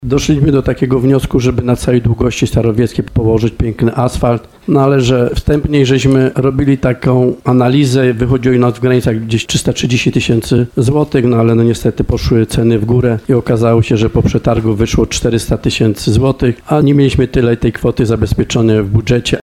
mówi wójt Stanisław Kuzak.